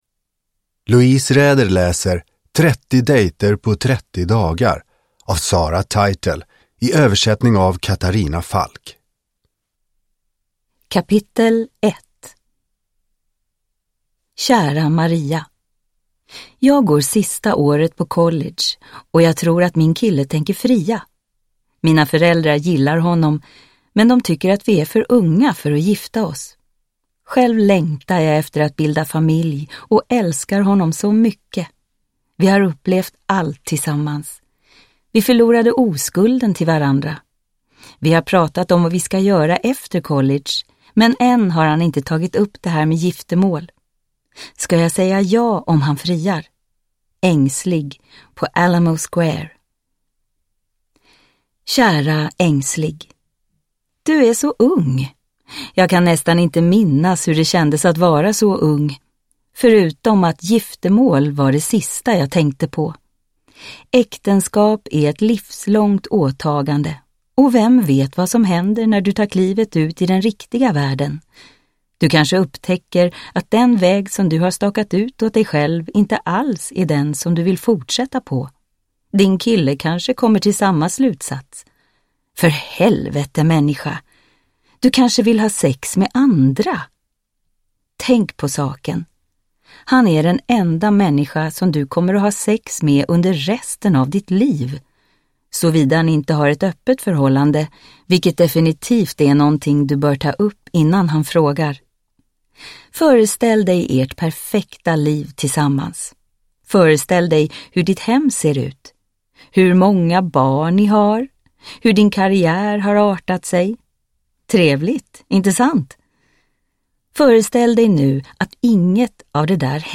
30 dejter på 30 dagar – Ljudbok – Laddas ner